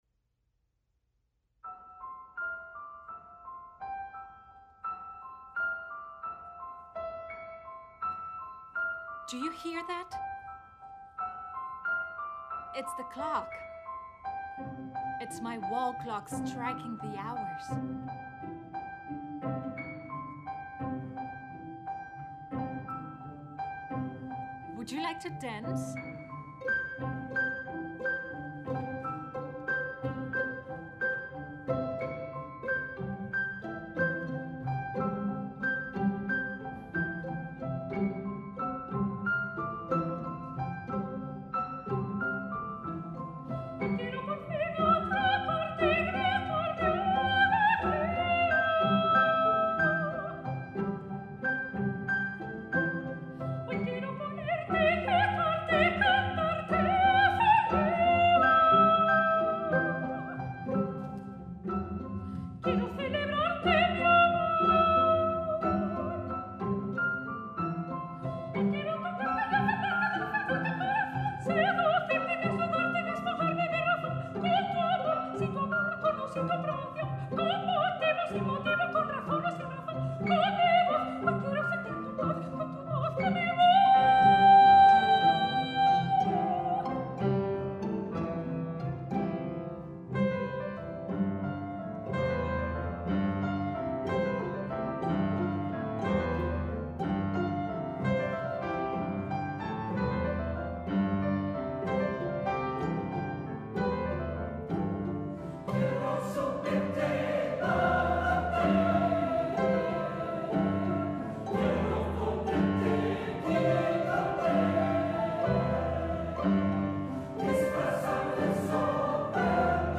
soprano
pianista